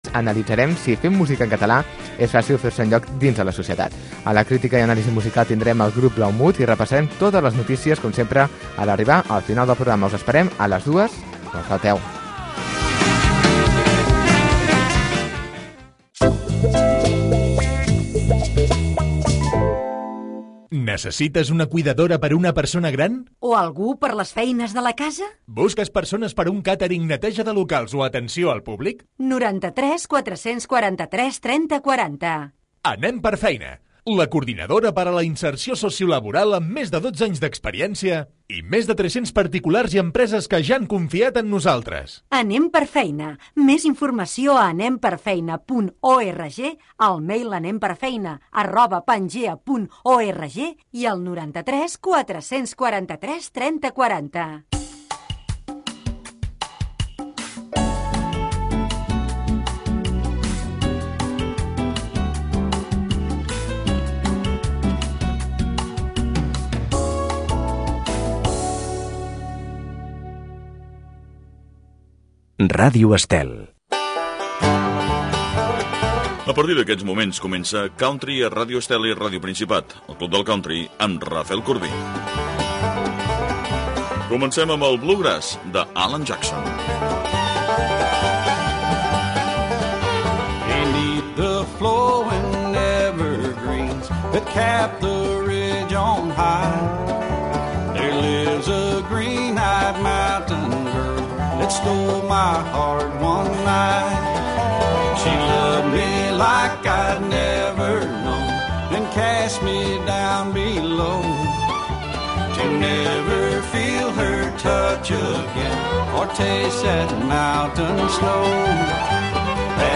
El club del country. Programa de música country. Durant 60 minuts escoltaràs els èxits del moment i els grans clàssics de la música country.